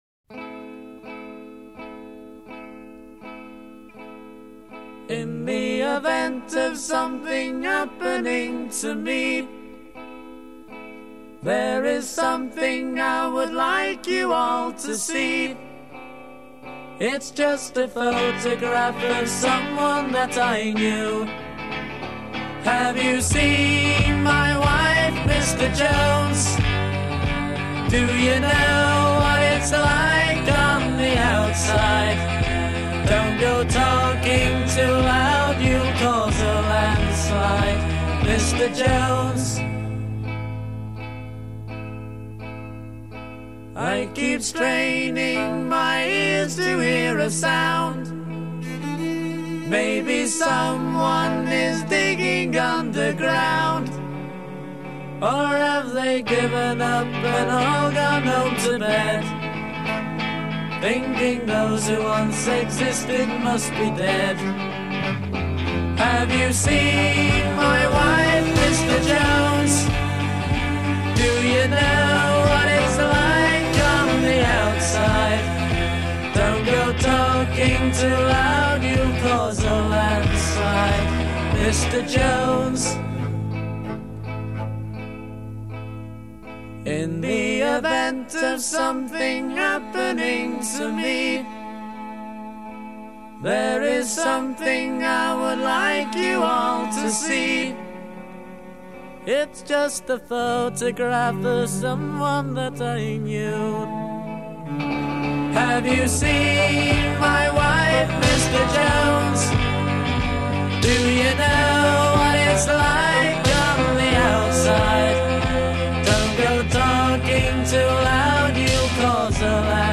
Recorded 16 March 1967, IBC Studios, London.
guitar & vocals
drums) with unidentified orchestral session musicians.
Introduction 2 Guitar, strummed chords.
Verse 8 Add cello to line c
Verse 6 Slow down. Voices with guitar and cello. a